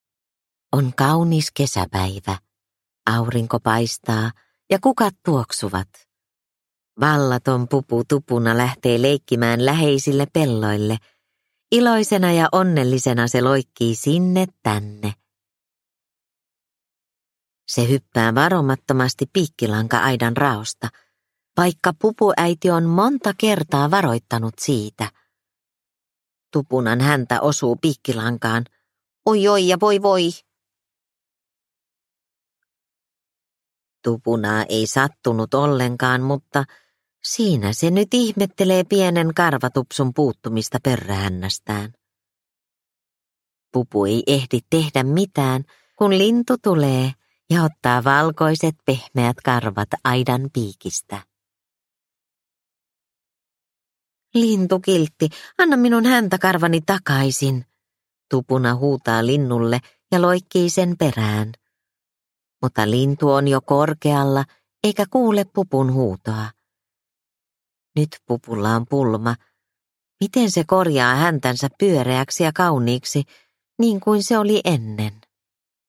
Pupu Tupunan pulma – Ljudbok – Laddas ner